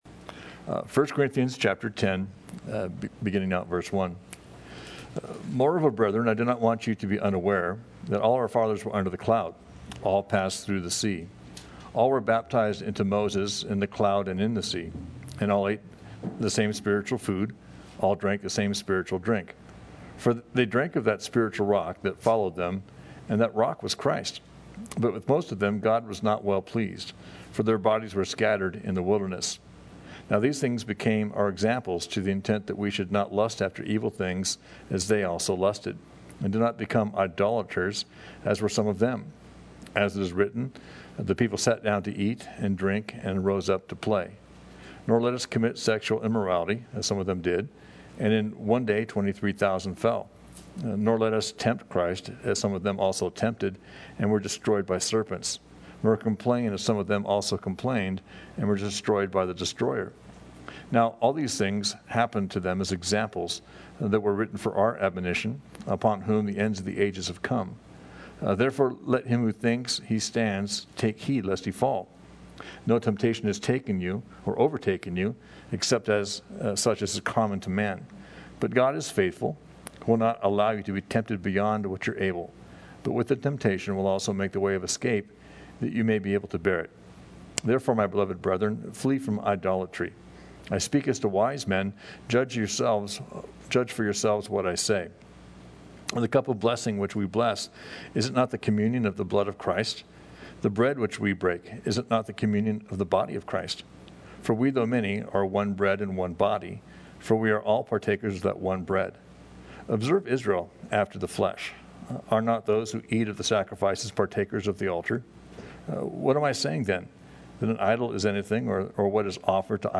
Thank’s for checking out our study in 1st Corinthians.